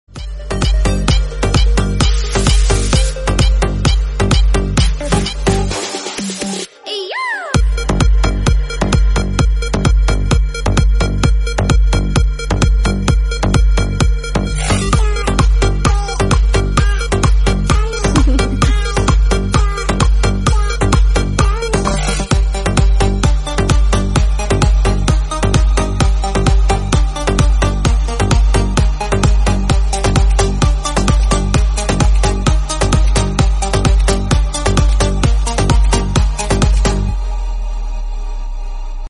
full bass in the sounds and full beats